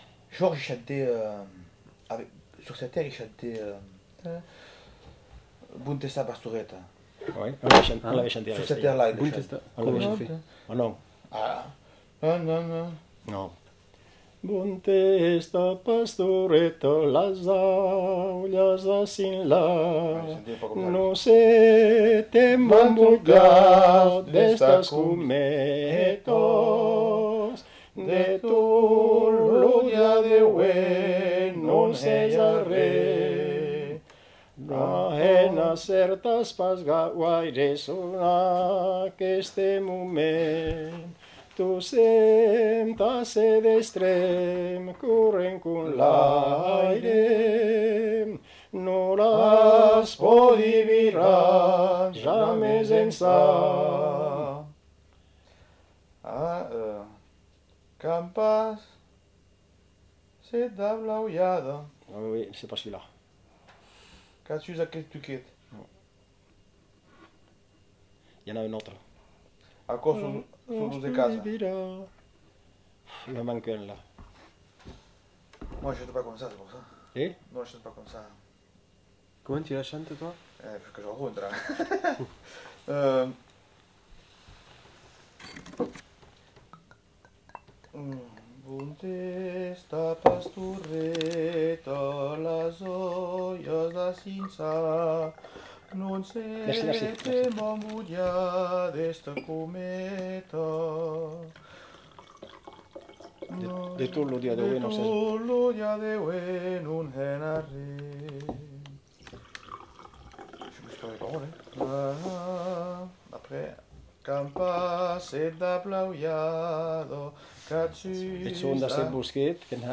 Aire culturelle : Bigorre
Lieu : Villelongue
Genre : chant
Type de voix : voix d'homme
Production du son : chanté ; récité
Notes consultables : Les deux interprètes chantent à tour de rôle pour se remémorer les paroles.